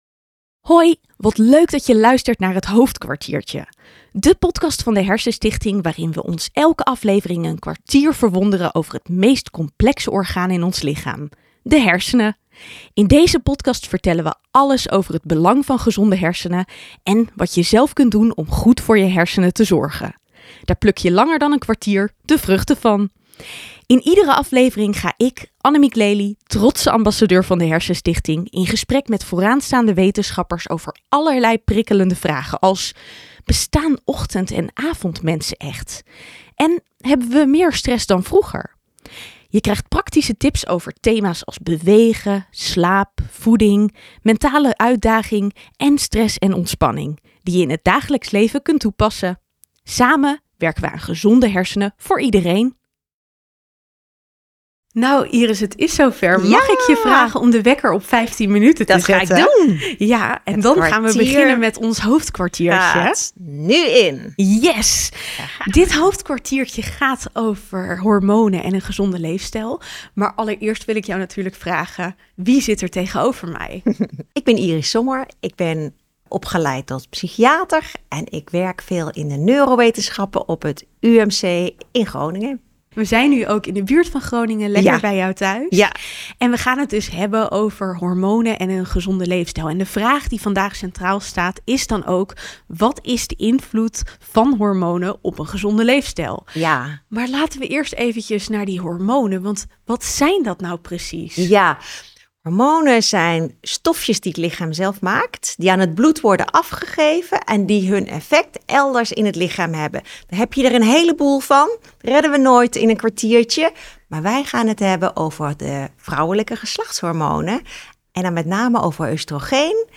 En wat zijn de verschillen tussen mannen en vrouwen? In deze aflevering van Het HoofdKwartiertje vertelt psychiater Iris Sommer je alles over de invloed van geslachtshormonen op hersengezondheid.